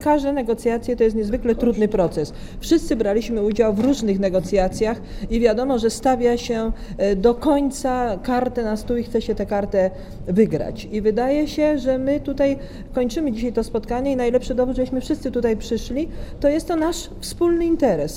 Teraz nie ma czasu na krytykę - mówi była premier Hanna Suchocka.